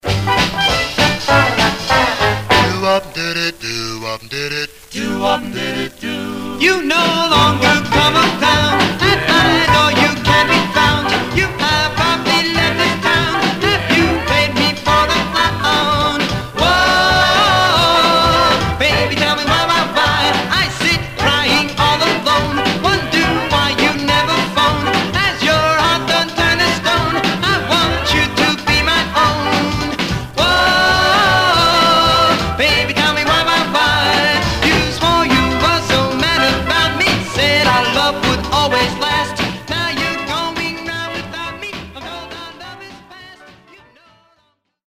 Surface noise/wear
Mono